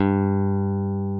P-B PICK G3.wav